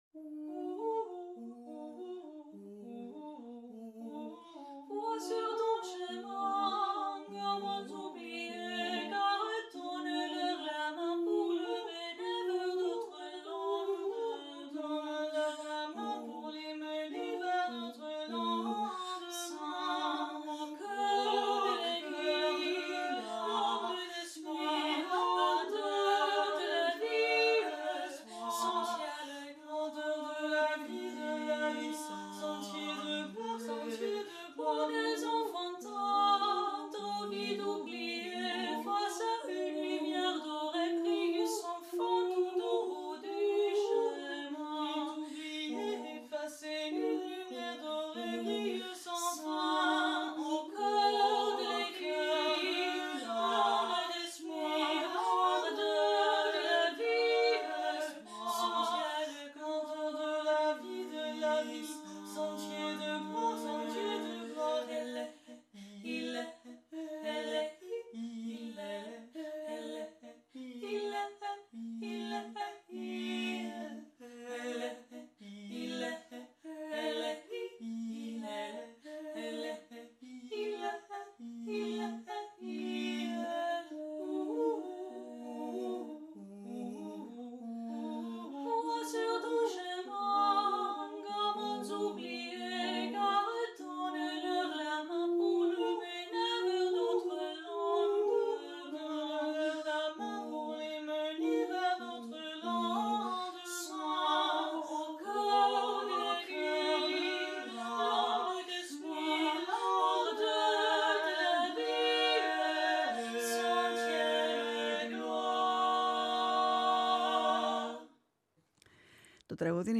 Μιλήσαμε για το φωνητικό σχήμα τους, την πορεια της καθεμιάς, ακουσαμε τραγουδια απο το ρεπερτόριό τους, τραγούδησαν live στο στούντιο.